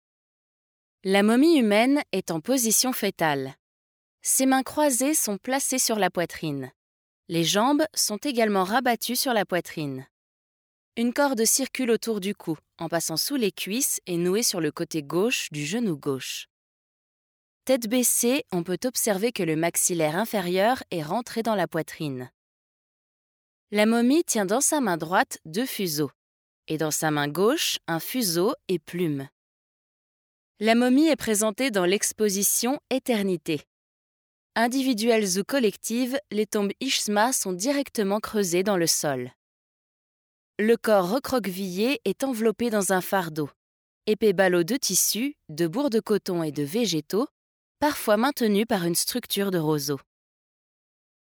Natürlich, Vielseitig, Freundlich
Audioguide